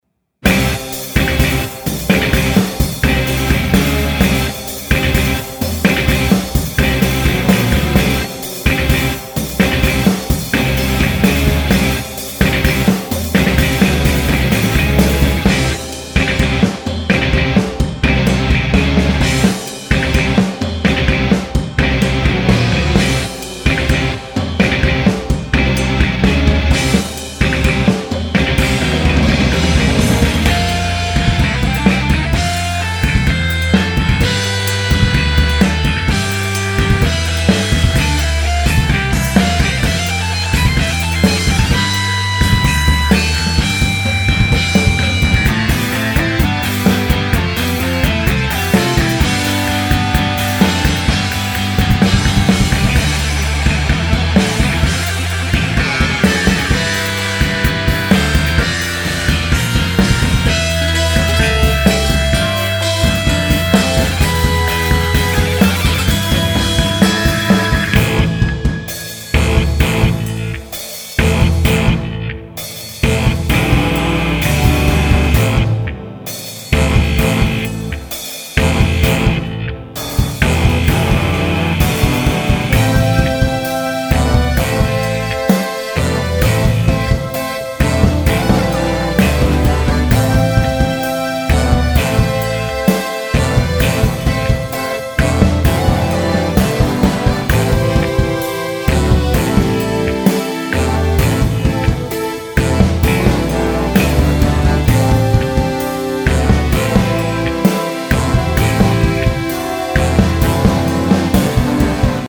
UADのコンプレッサー様様のおかげでその辺みんな解決してやっと音楽っぽくなってきた。
キックの音がボワってるのでもっとタイトにしたいのとハイハットをもっとザラザラさせられたら言う事なしだな。